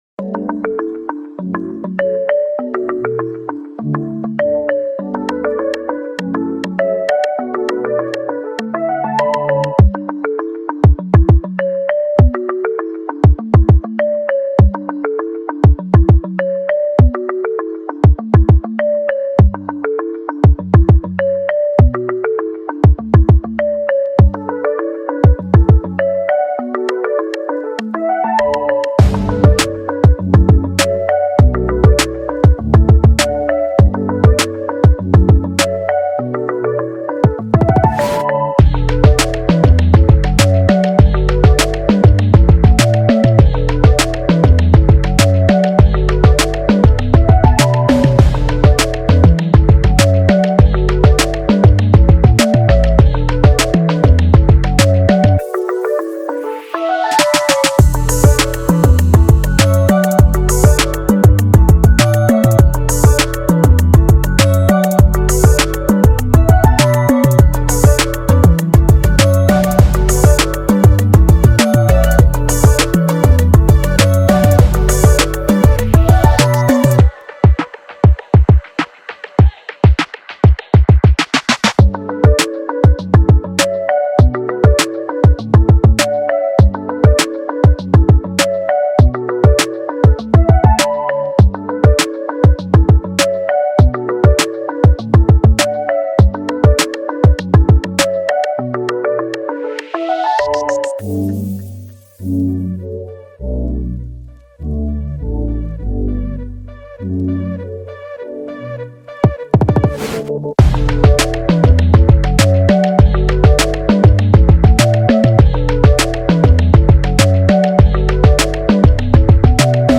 Pop Instrumental